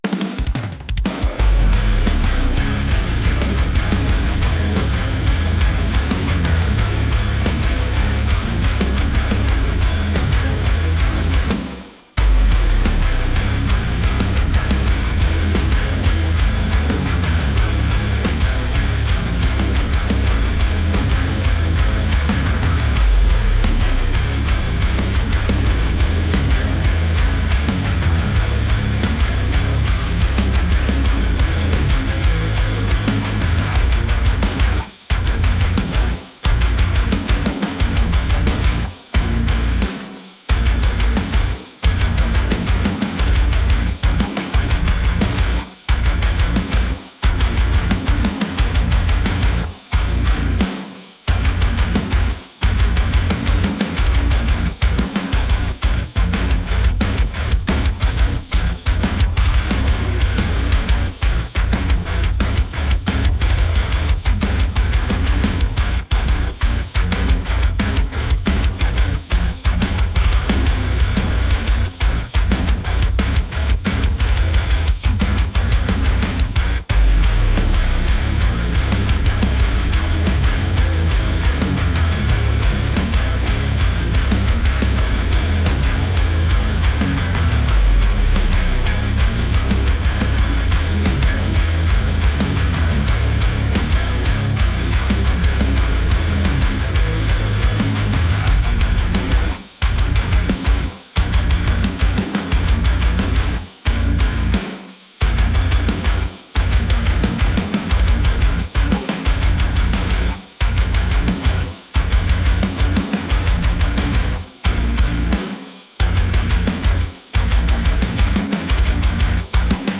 metal | aggressive